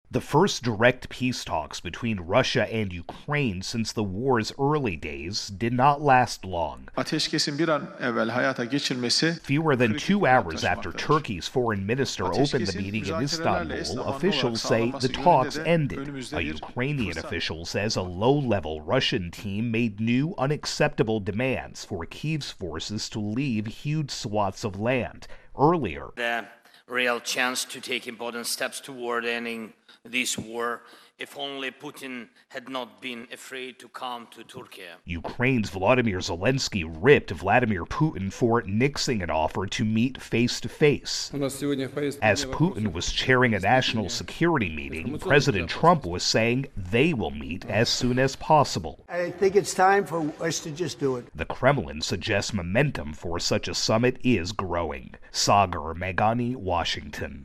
AP Washington correspondent reports on direct Russian-Ukrainian peace talks ending quickly in Turkey.